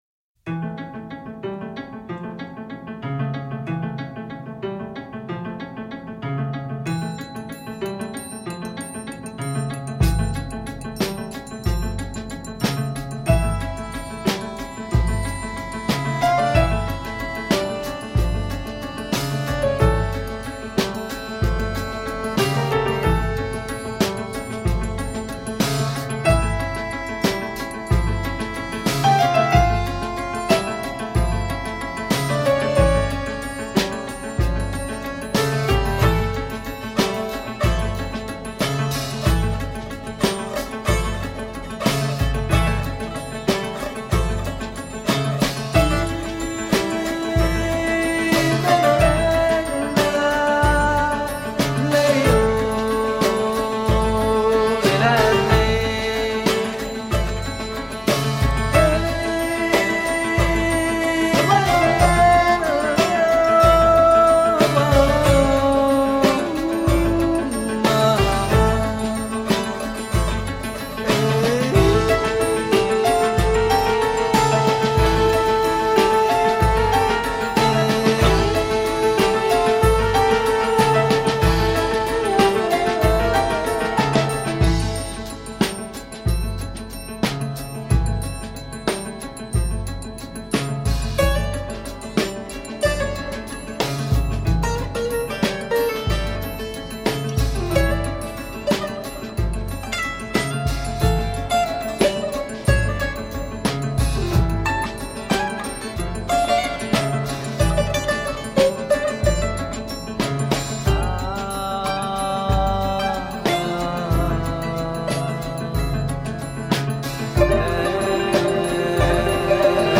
Middle eastern/balkan music and new age.
Oud, Bouzouki, saz, Flutes and Vocals
Piano, Accordion and Harmonium
Tabla, Drum set and Frame drums